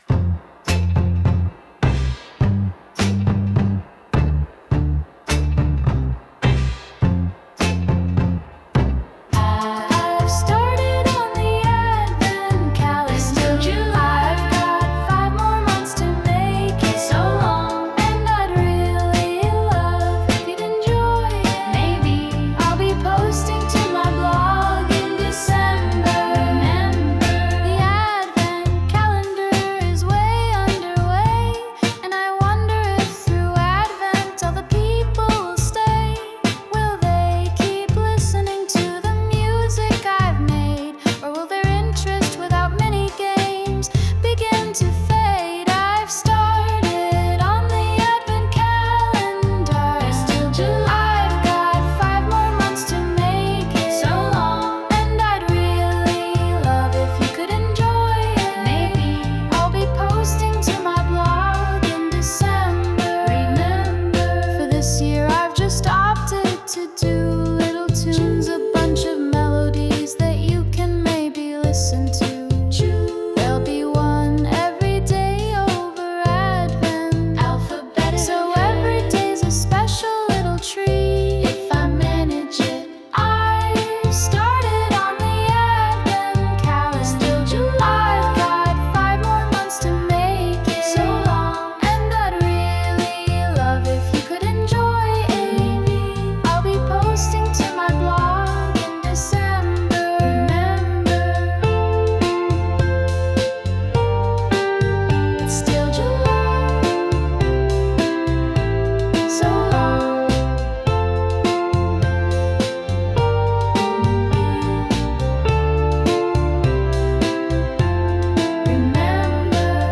Sound Imported : Recorded Sleighbells
Sung by Suno